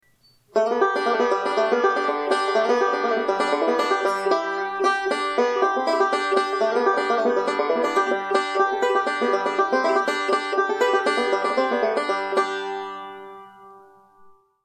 Gold Tone AC-Traveller Banjo w/gigbag - $235 + $65 S/H/I (US only)
Even as an open back, this banjo has a nice tone!